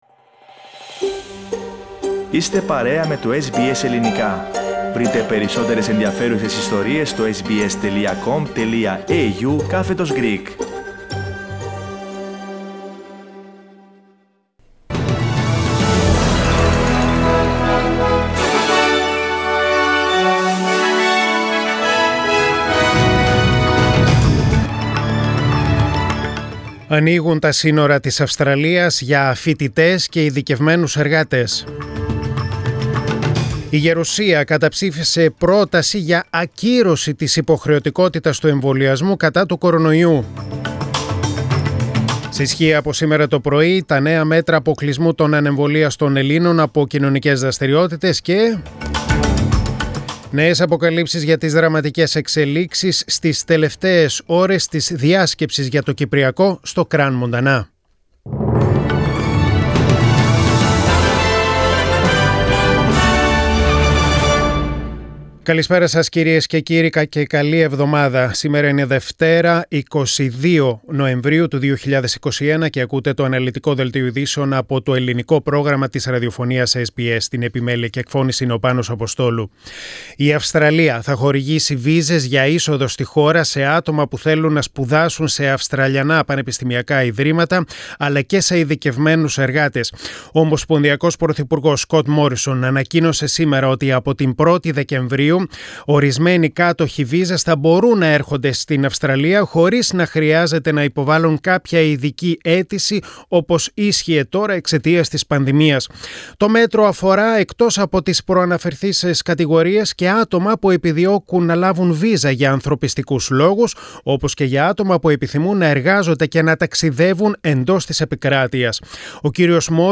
News in Greek: Monday 22.11.2021